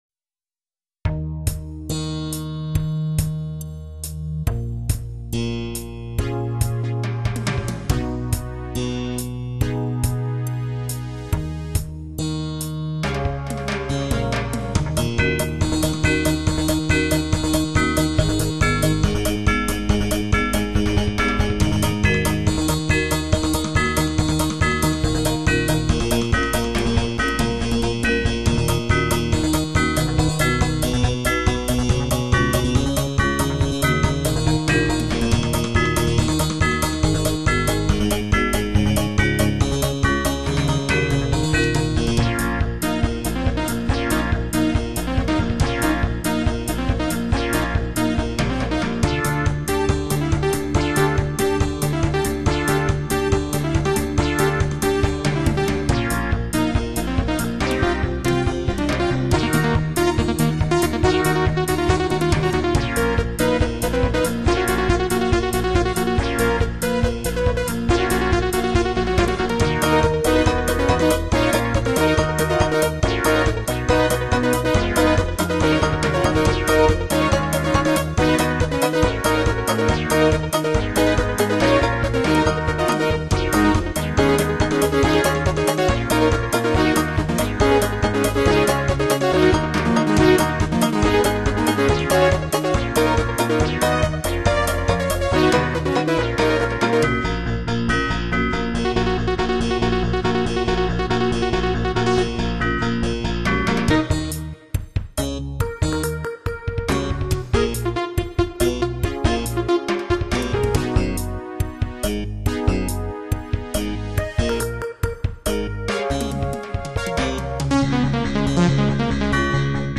Modern